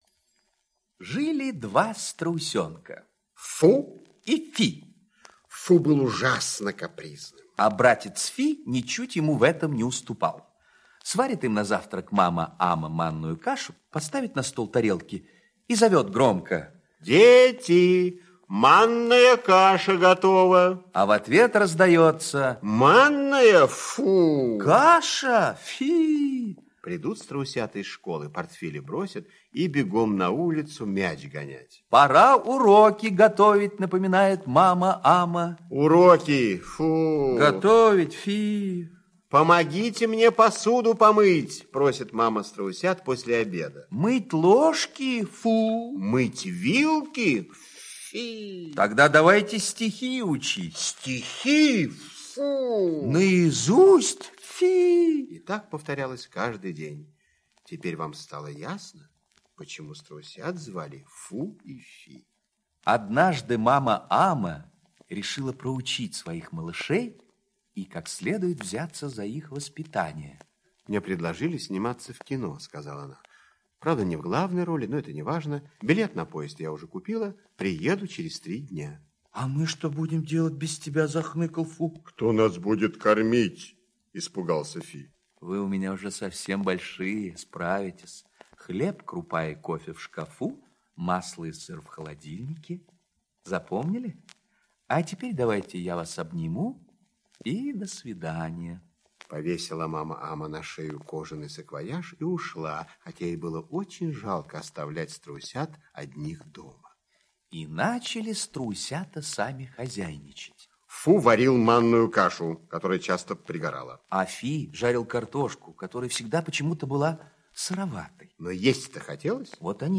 Слушайте Счастливый день - аудиосказка Пляцковского М.С. Сказка про двух страусят, которые ничего не делали и на все отвечали :"Фи" и "Фу".